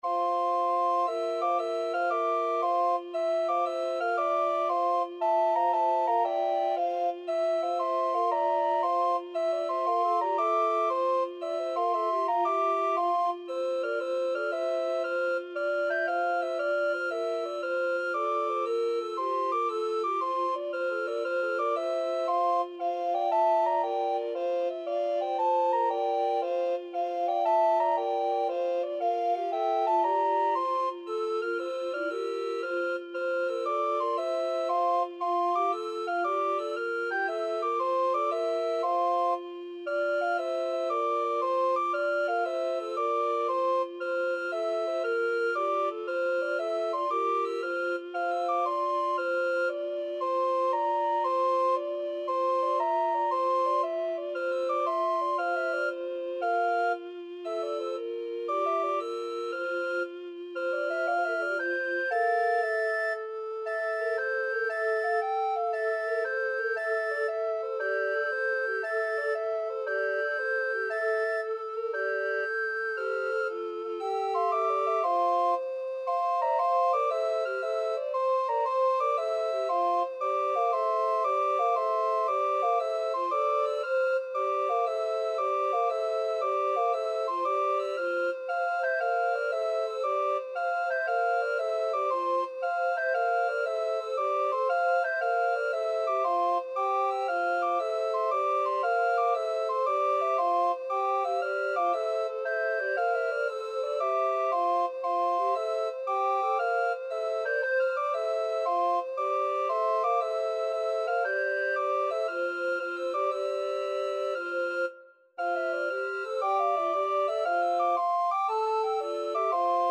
Pérotin - Viderunt Omnes Free Sheet music for Recorder Quartet
Soprano Recorder Alto Recorder Tenor Recorder Bass Recorder
Tempo Marking: . = 116
Key: F major (Sounding Pitch)
Time Signature: 6/8
Style: Classical